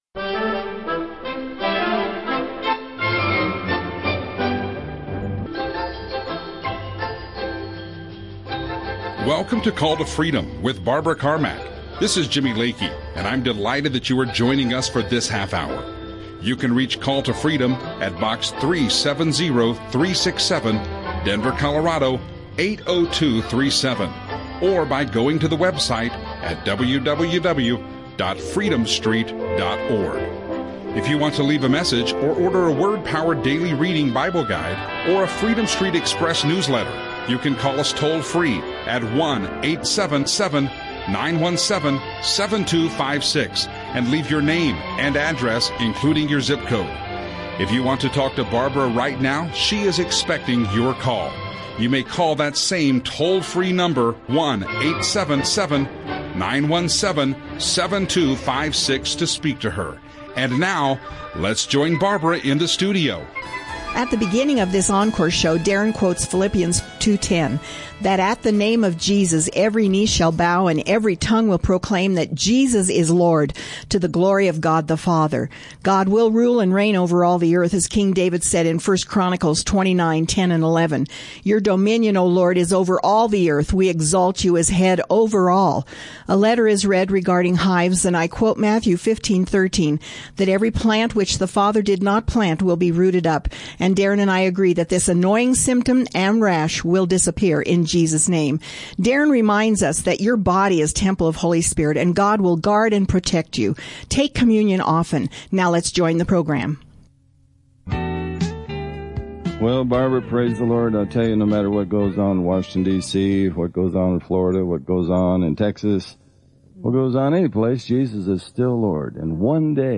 We talk some phone calls that include praying against hives and rashes; it's an honor to be able to take calls and know that those prayers, in the Name of JESUS, will bring supernatural results.
Call to Freedom Christian talk
radio ministry